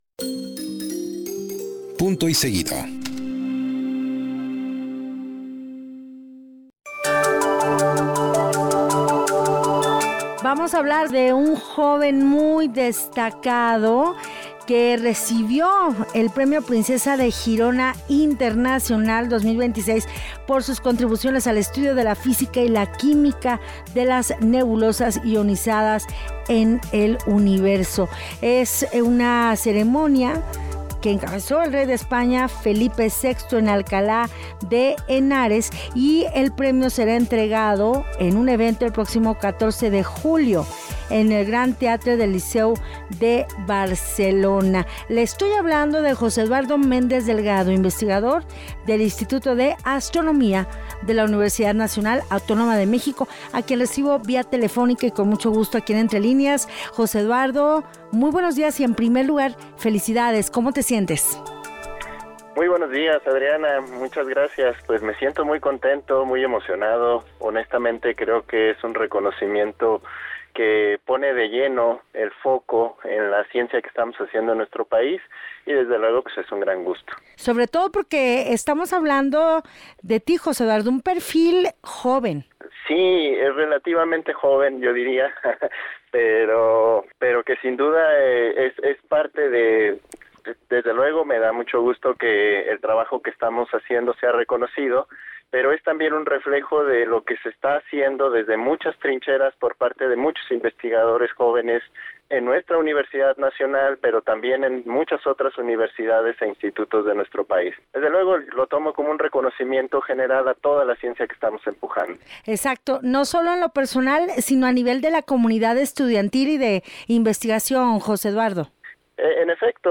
En entrevista para Entrelíneas